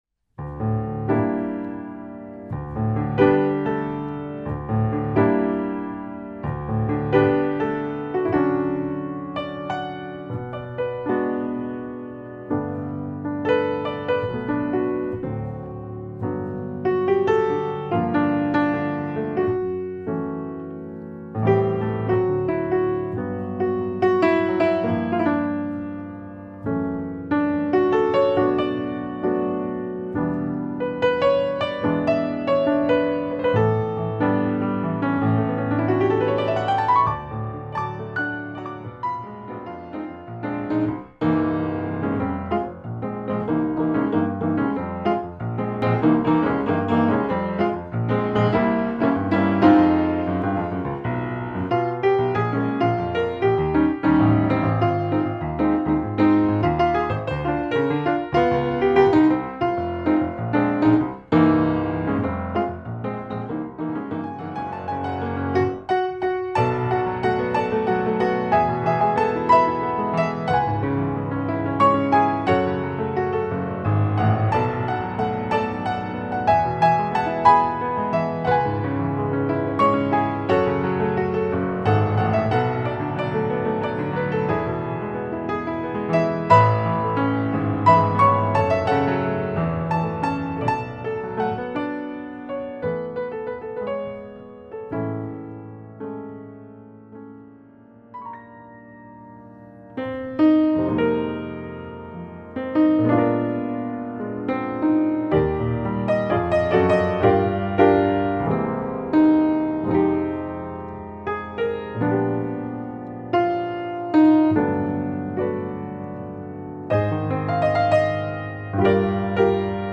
• Styles ranging from classical, pop, jazz or film music
• Solo